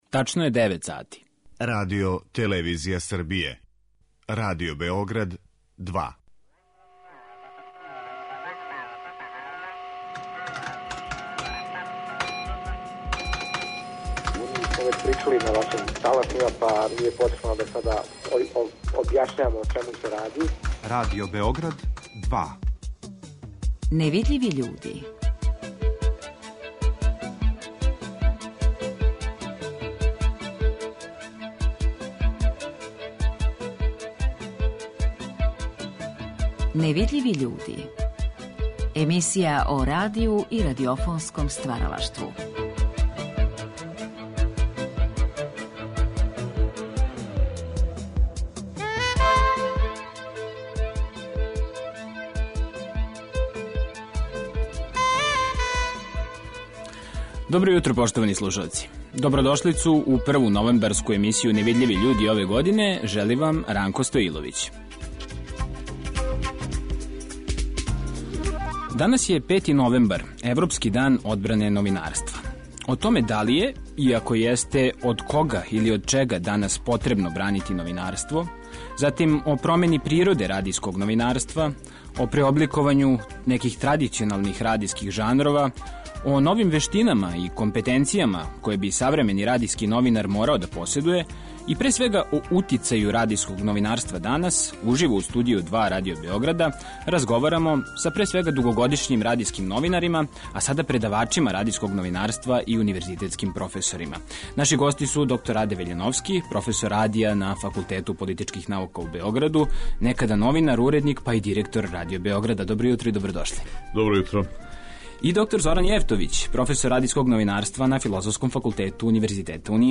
О промени природе радијског новинарства, о преобликовању традиционалних радијских жанрова, о новим вештинама и компетенцијама које би савремени радијски новинар морао да поседује и, пре свега, о утицају радијског новинарства данас, уживо у Студију 2 Радио Београда разговарамо са дугогодишњим радијским новинарима, а сада предавачима радијског новинарства и универзитетским професорима.